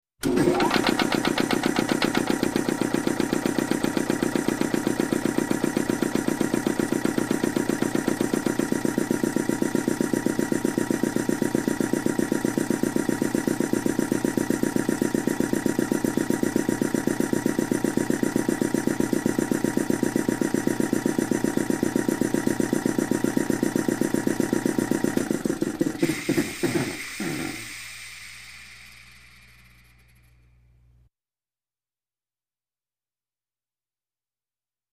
Compressor
Motorized Shop Tool, Compressor Motor 2; Turn On, Steady Chugging Motor, And Off With Chug.